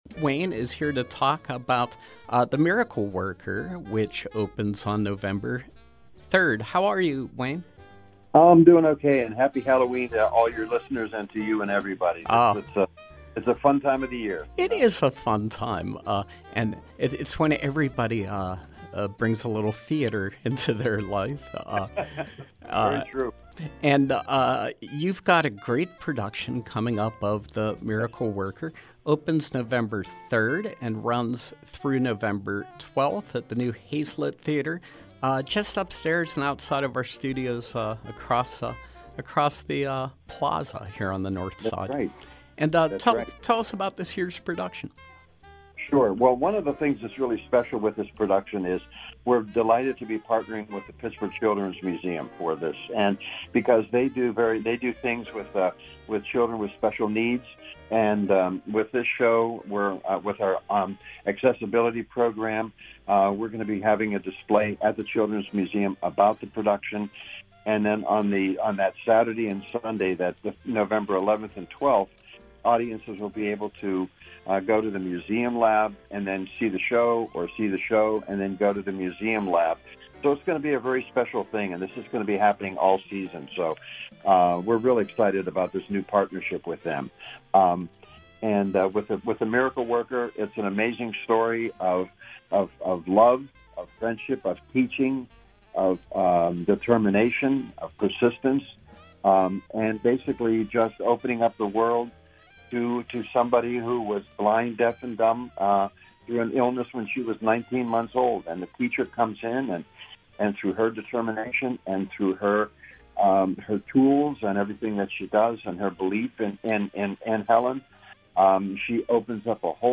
Interview: The Miracle Worker, Prime Stage Theatre